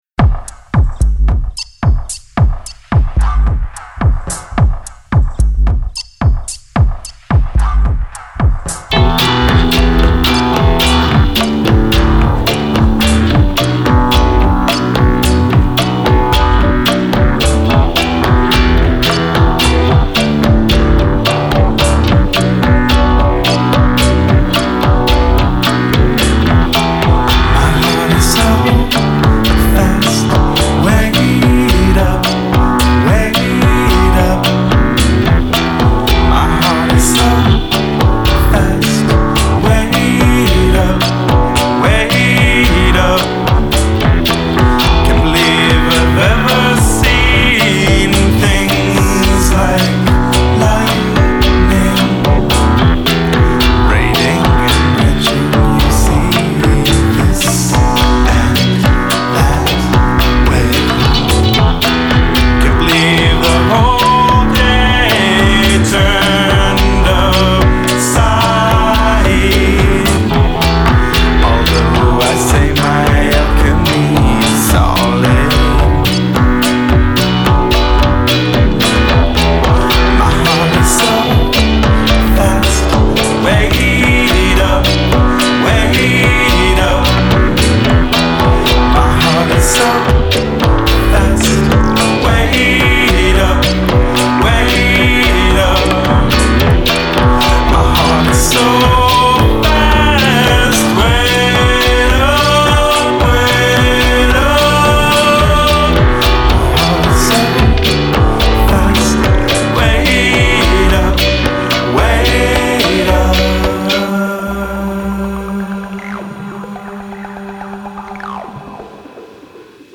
essentially an acoustic folk pop sounding artist
almost a fringe electro folk reggae beat of a track